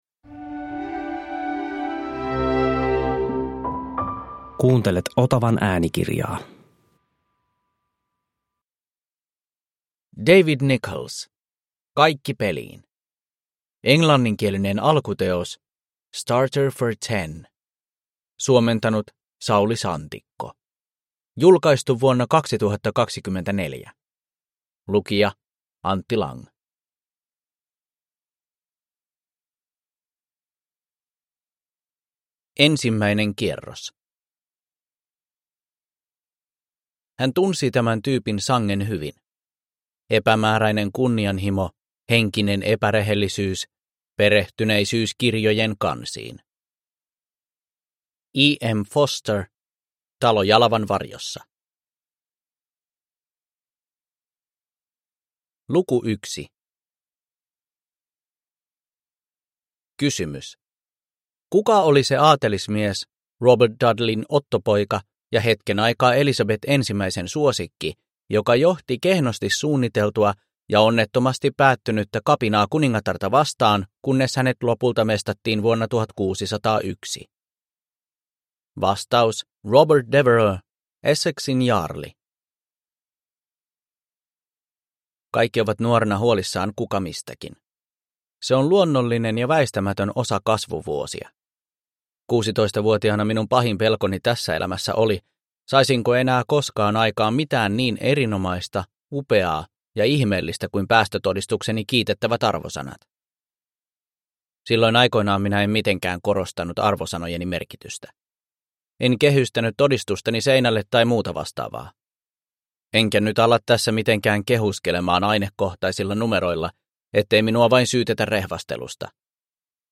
Kaikki peliin – Ljudbok